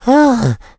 Below lies a collection of voice clips and sound effects from the first in the Mario Galaxy series!